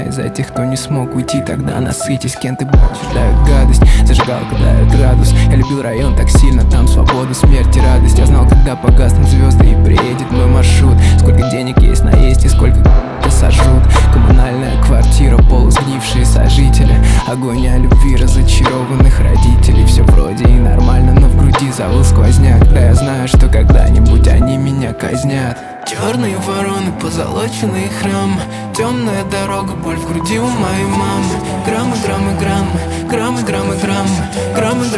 Жанр: Хип-Хоп / Рэп / Рок / Русский рэп / Русские
Hip-Hop, Rap, Rock